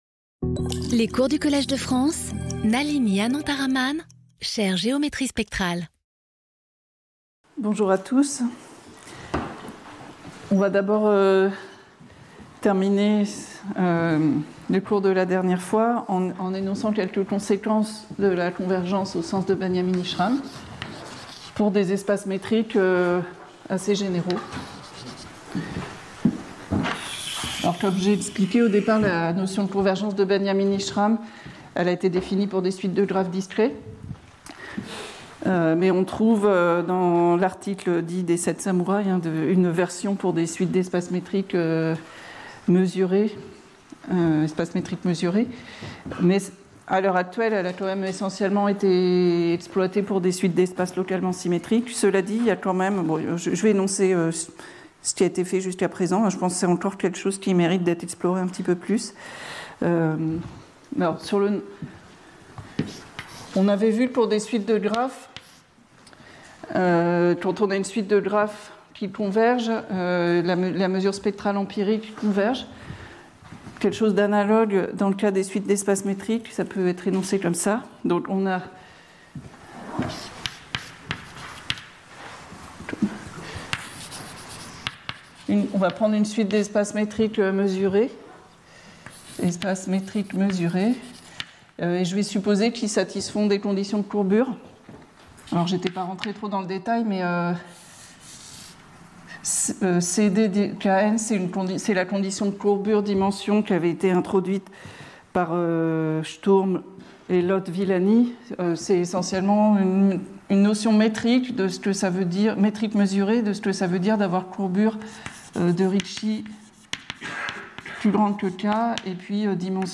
Intervenant(s) Nalini Anantharaman Professeure du Collège de France
Cours